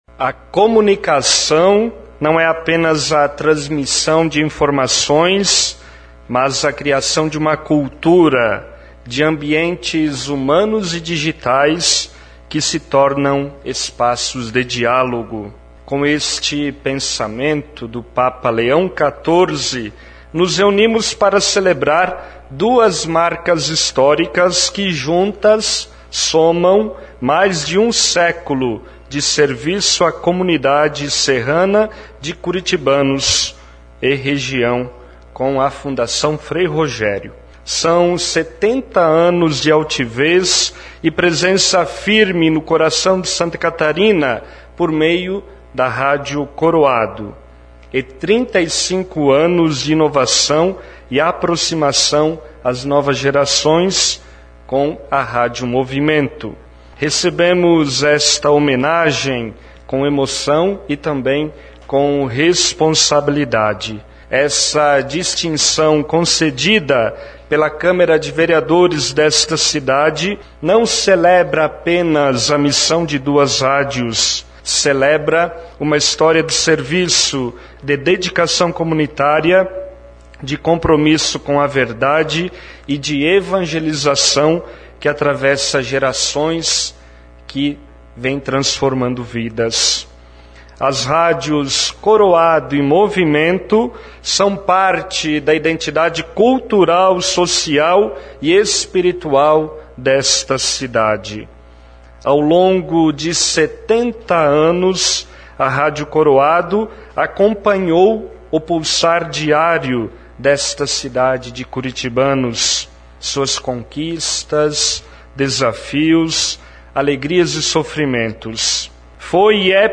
SESSÃO-CAMARA.mp3